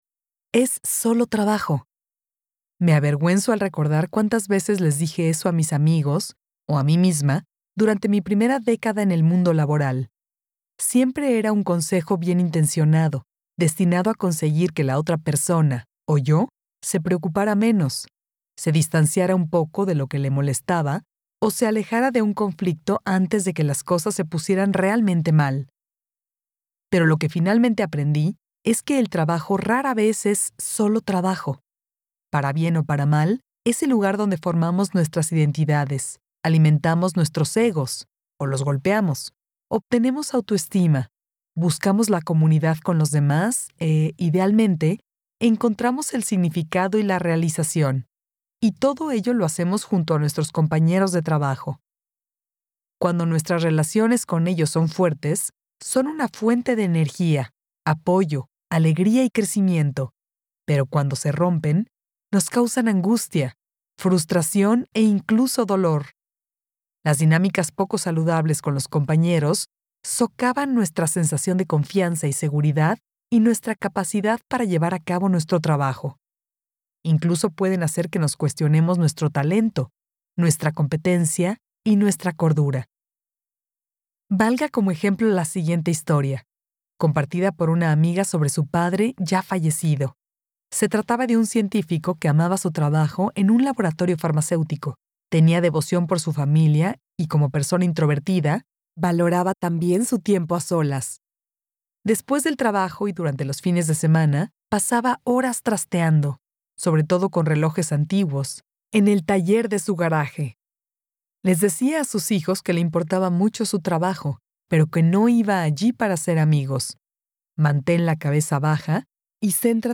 Audiolibro Conecta (Getting Along)